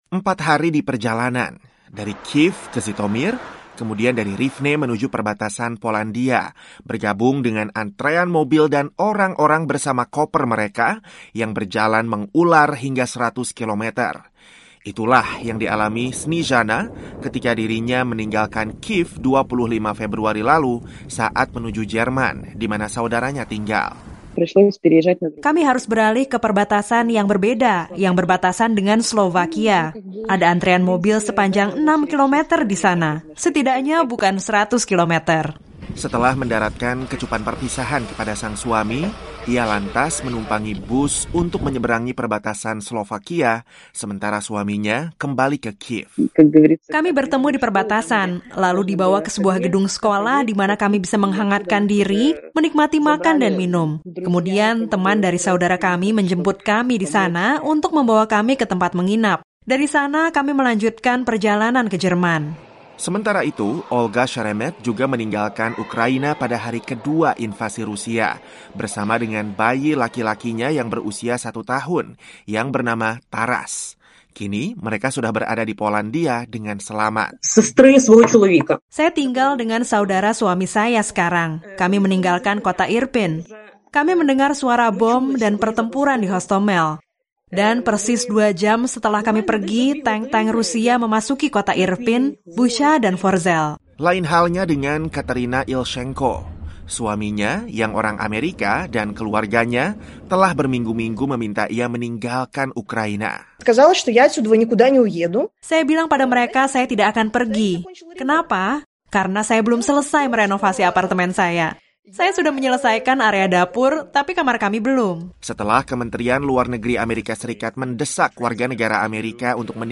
Menurut Perserikatan Bangsa Bangsa (PBB), lebih dari 500.000 pengungsi telah meninggalkan Ukraina dan puluhan ribu lainnya juga melakukan hal yang sama setiap harinya. VOA mewawancarai beberapa perempuan dan anak yang berada dalam rombongan pengungsi untuk menyelamatkan diri dari invasi Rusia.